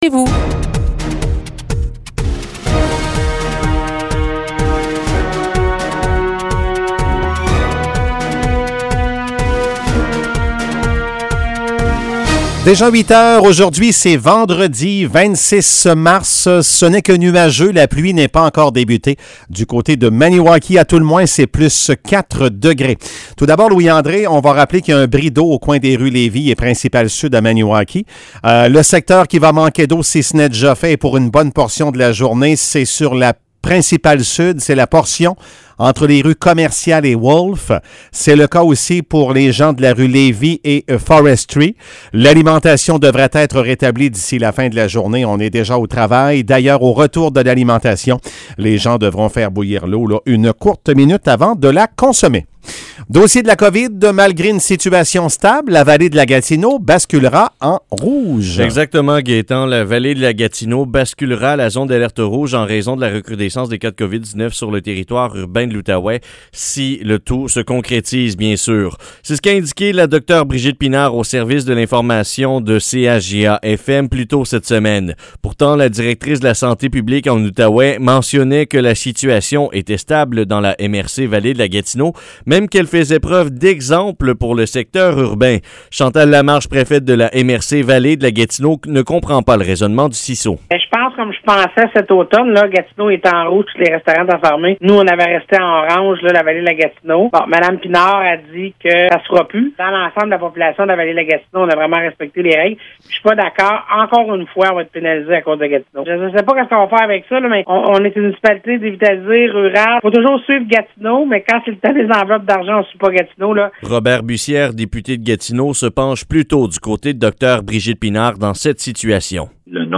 Nouvelles locales - 26 mars 2021 - 8 h